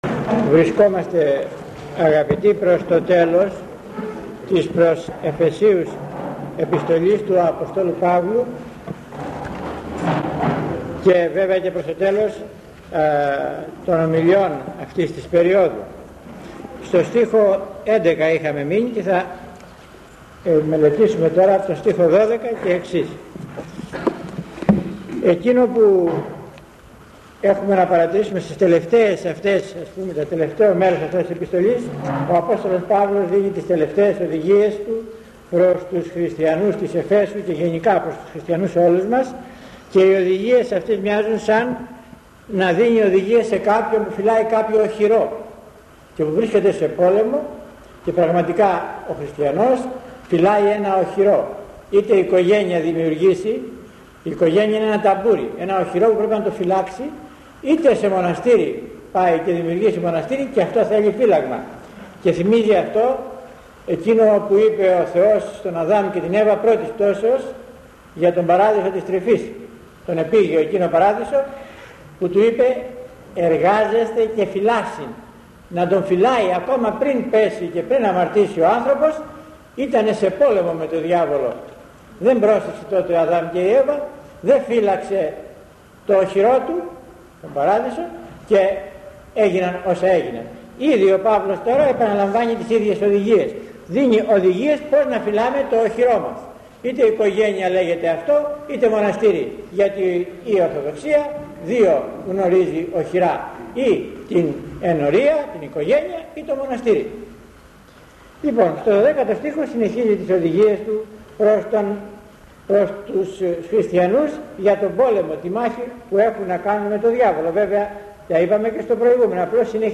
και τώρα η 10η ομιλία ερμηνείας της προς Εφεσίους Επιστολής…